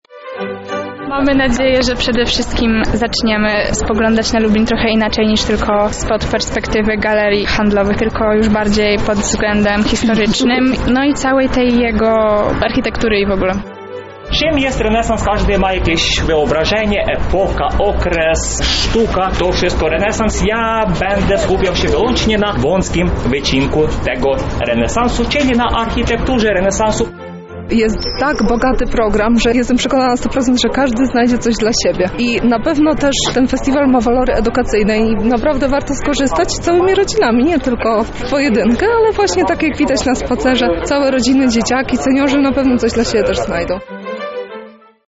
O refleksje dotyczące festiwalu zapytaliśmy uczestników spaceru: